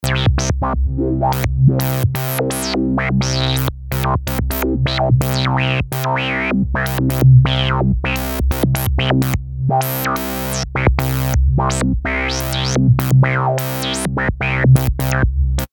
Subtractor in Reason is ready to perform its random LFO treatment.
The random LFO patch used.